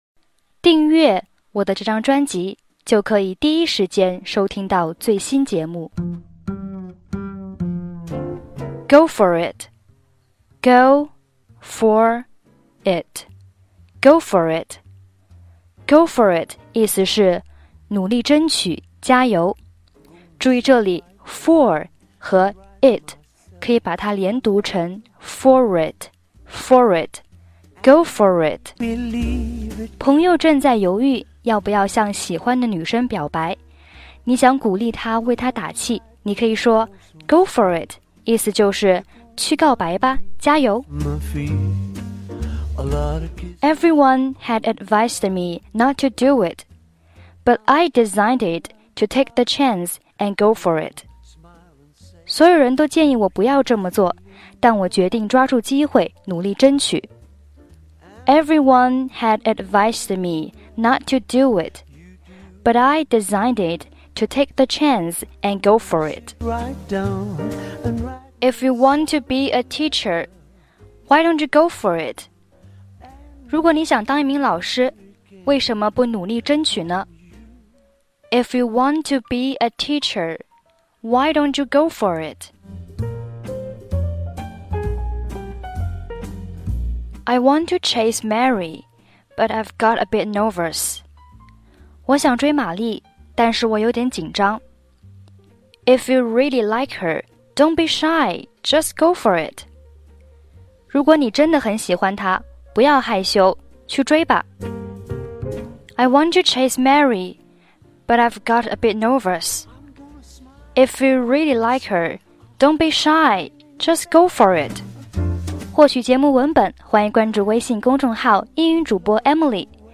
背景音乐：